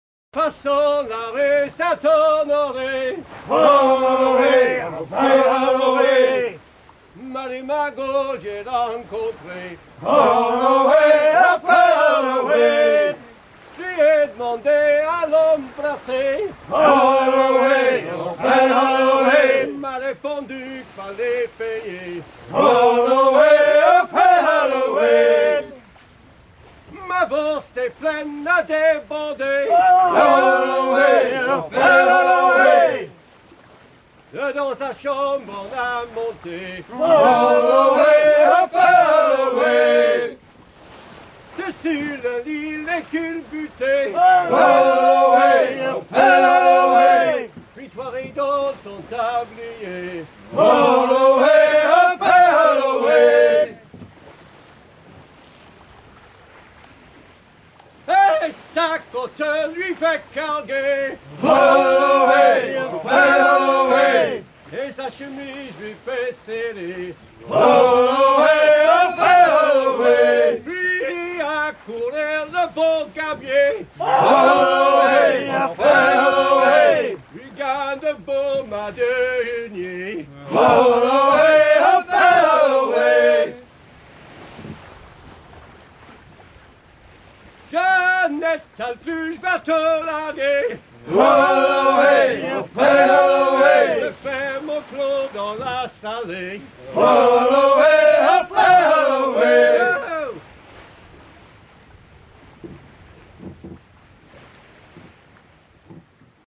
Le chant est interprété en hissant les voiles de la goélette à hunier